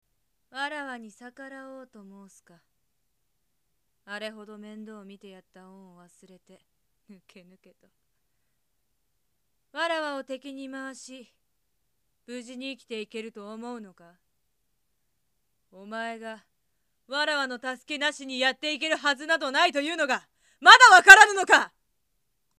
演技サンプル
01.　通る声の少年／旅立ち
02.　通る声の少女／メソメソ
04.　お姉さん／若
06.　おとなしい少女／バレンタイン大作戦
07.　低めの少年／明日の自分へ
08.　おとなしめの少年／ポジティブ